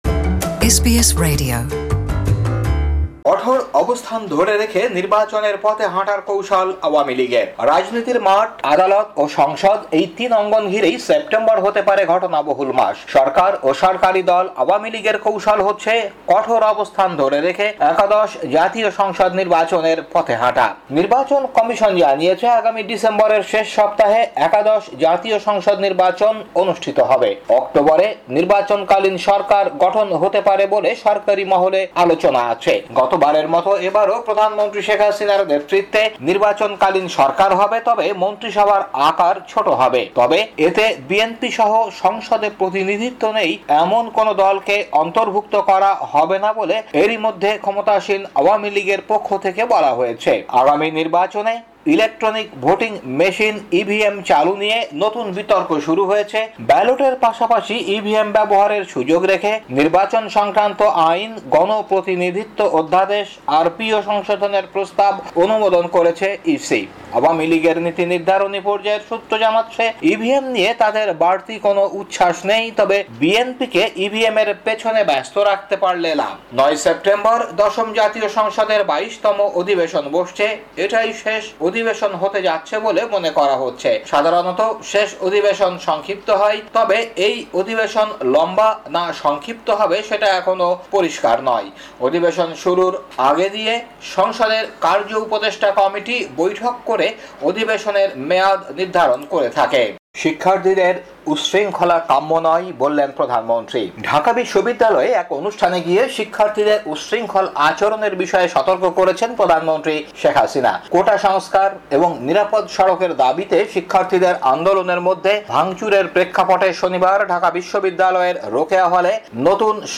বাংলাদেশী সংবাদ বিশ্লেষণ: ৩ সেপ্টেম্বর ২০১৮